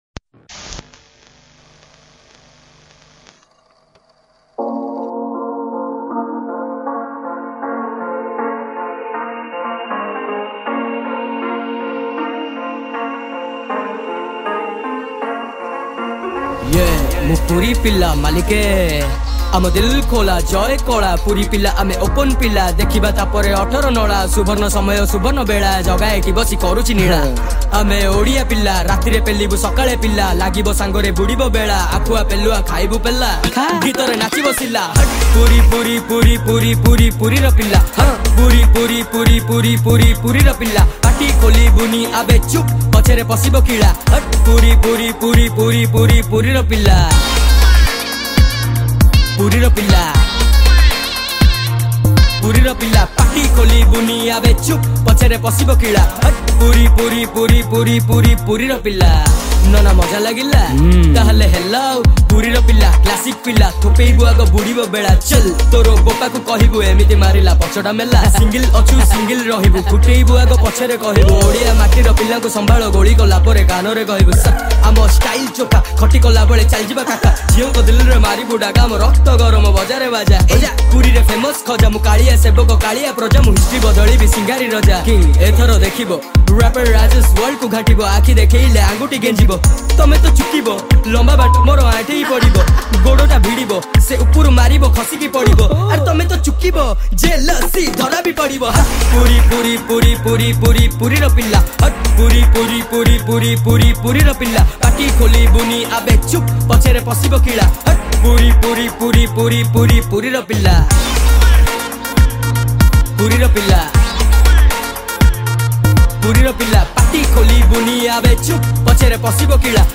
Odia Rap Song